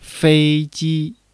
fei1-ji1.mp3